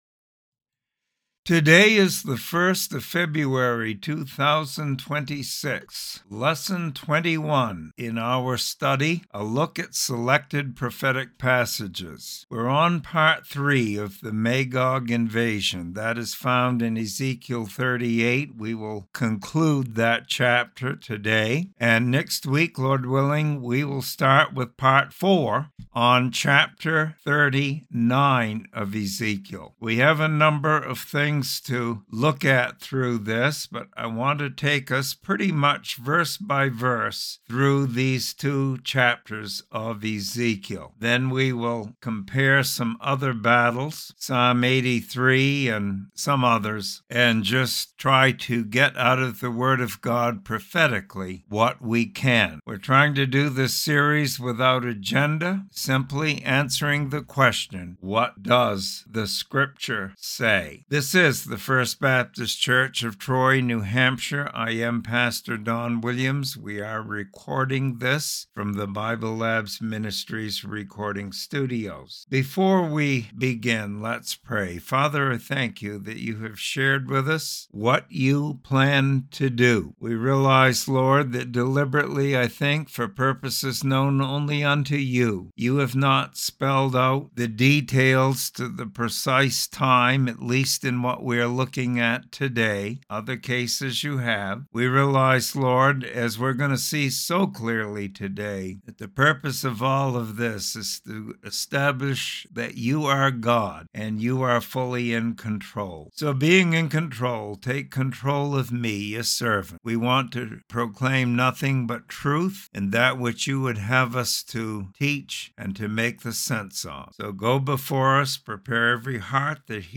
Bible Study and Commentary on Ezekiel 38:14-23. A confederation of Northern nations invade Israel and God miraculously delivers Israel.